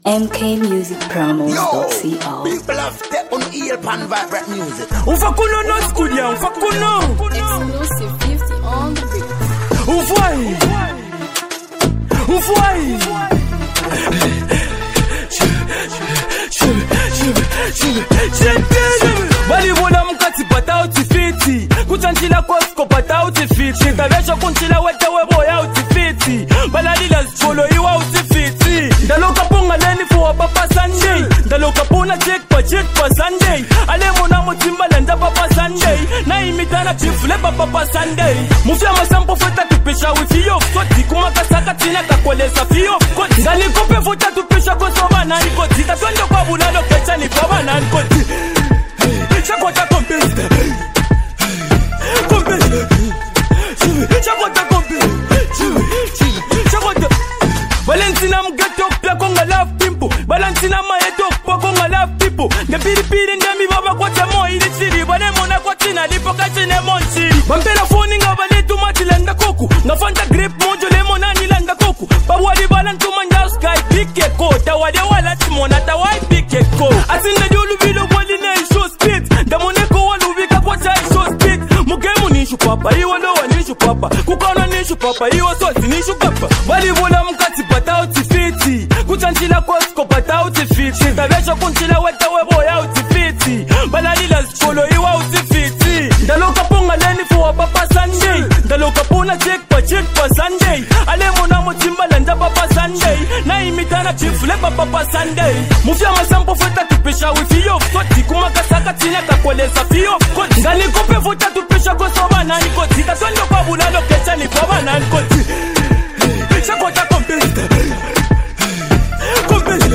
Zambian Hip-Hop 2026